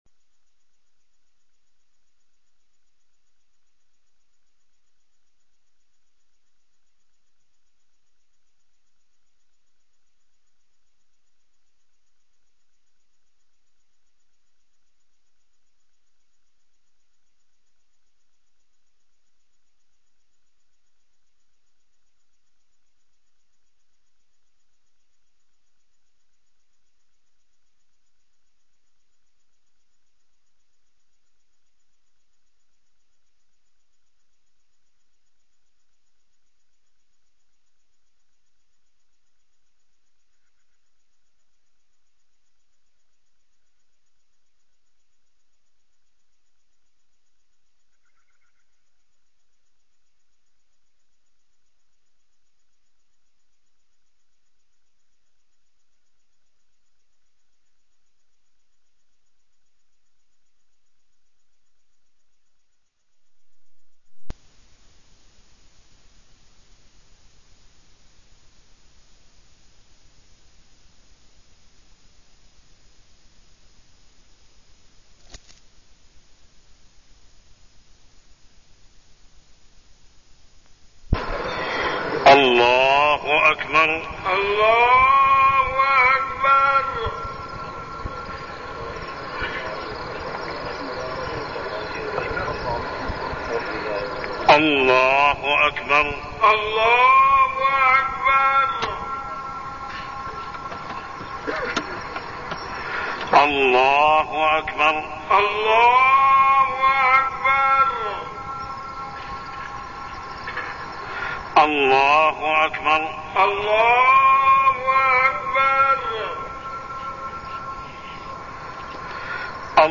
تاريخ النشر ٢٠ شعبان ١٤١٤ هـ المكان: المسجد الحرام الشيخ: محمد بن عبد الله السبيل محمد بن عبد الله السبيل الذنوب والمعاصي